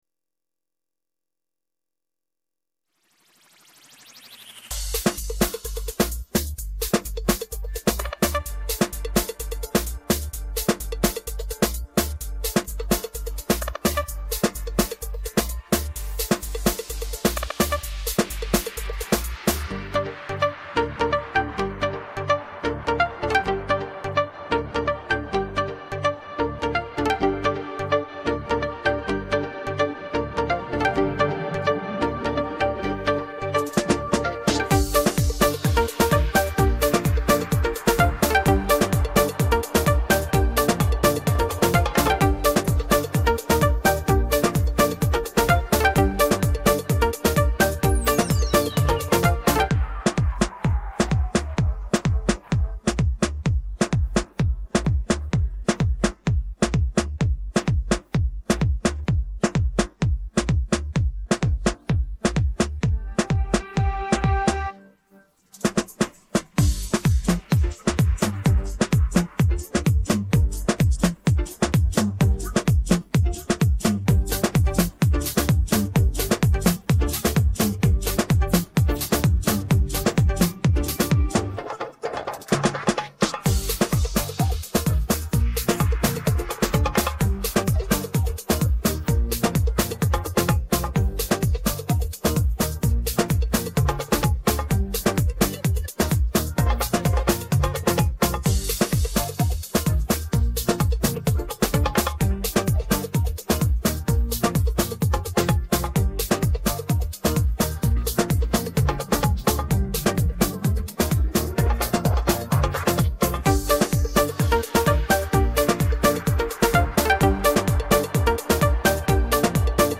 Muzik latar